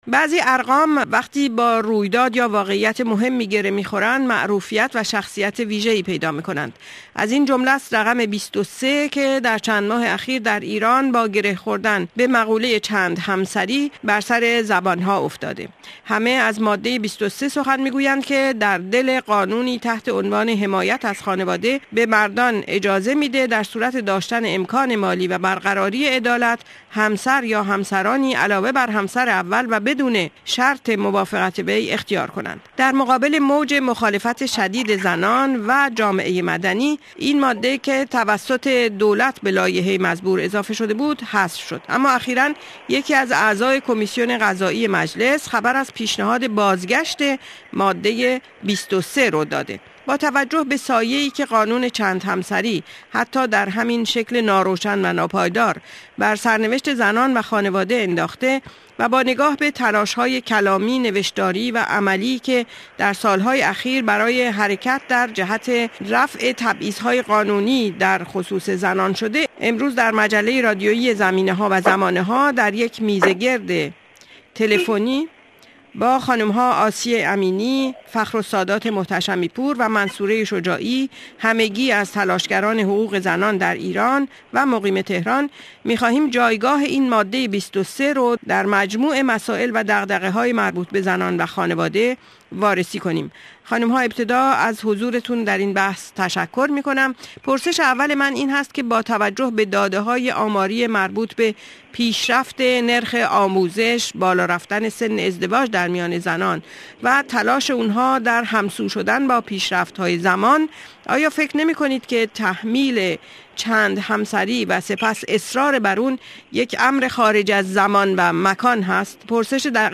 این میز گرد را گوش کنید